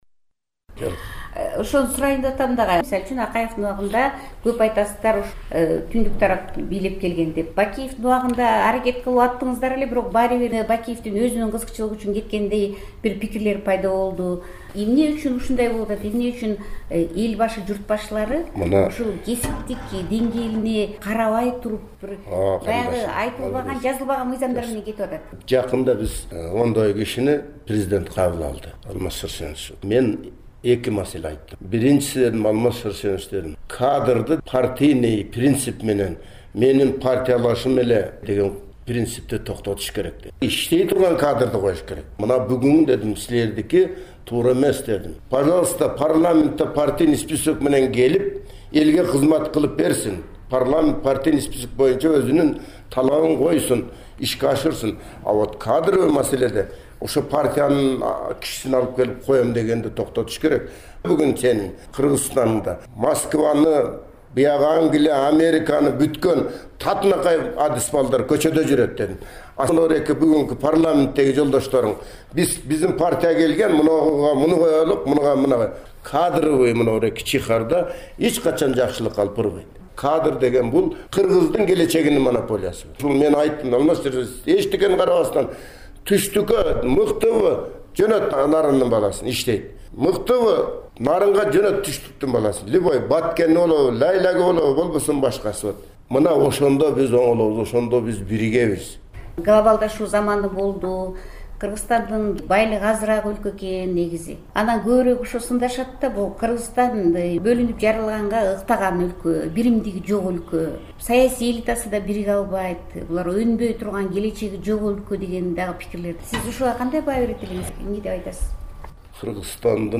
Маектин биринчи бөлүгү